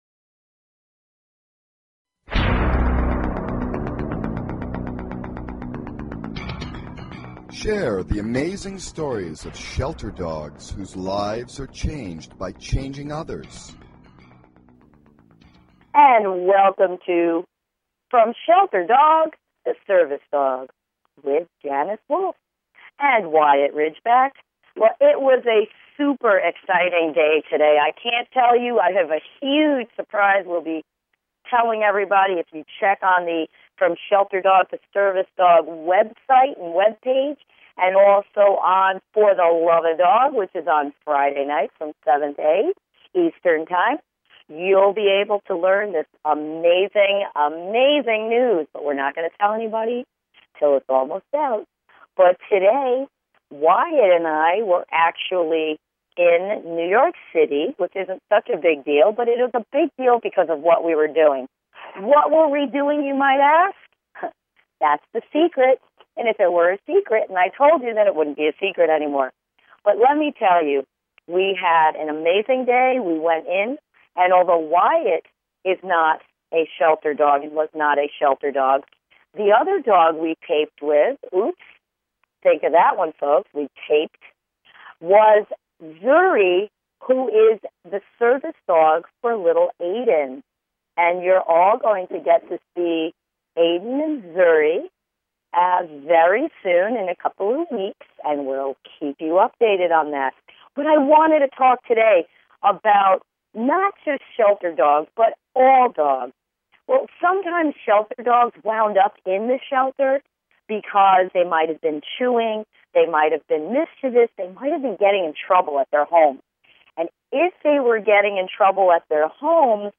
Talk Show Episode, Audio Podcast, From Shelter Dog to Service Dog